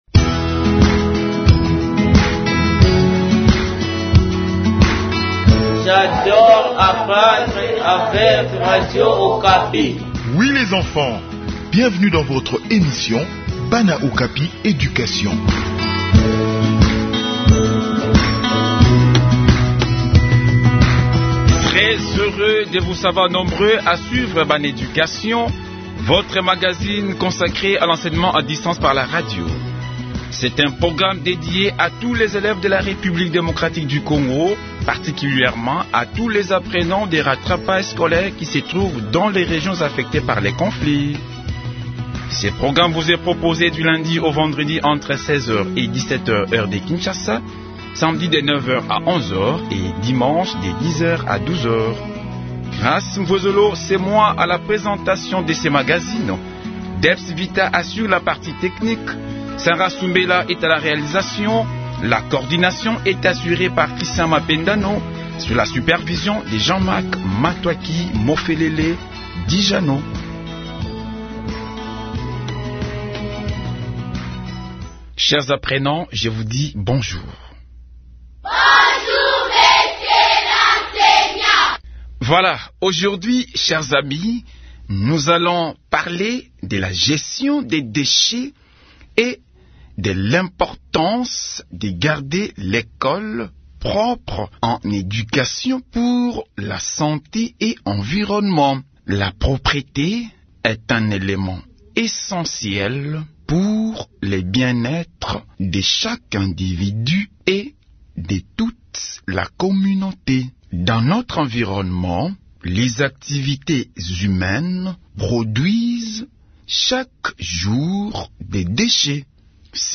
Enseignement à distance: leçon sur la bonne gestion des déchets à l'école